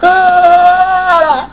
SPEECH FROM THE MOVIE :
- Detective #2's screams of terror as he throws his socks at LeJohn in defence. (12.0 kb)